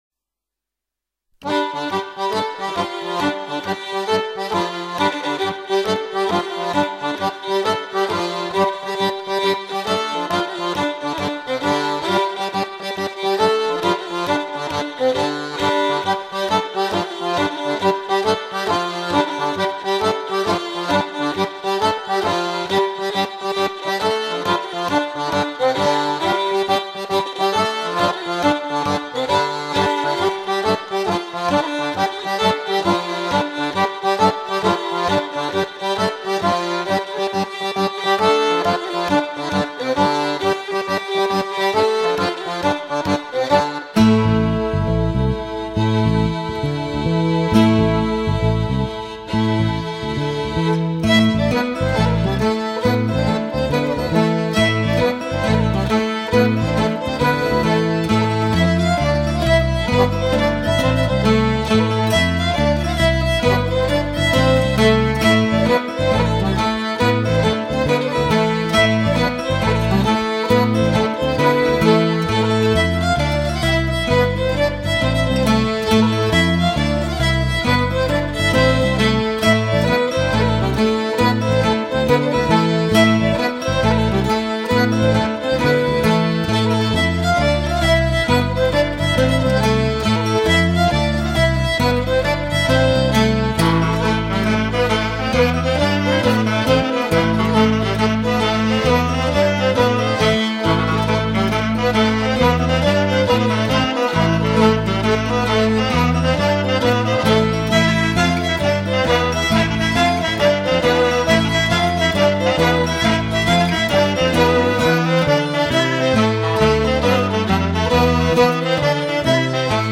Trois airs de laridés du Pays vannetais
danse : laridé, ridée
Pièce musicale éditée